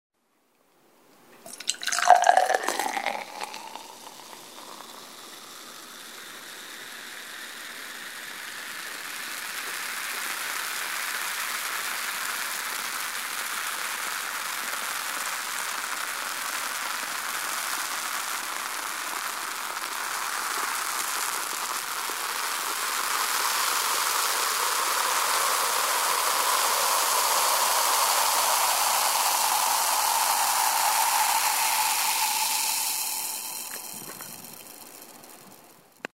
次に、これをシードルという炭酸の強いお酒でやってみました。
「シュワシュワシュワ・・・」という泡がはじける音がしてますね。
シードルの方が、圧倒的に美味しそうな音に聴こえます。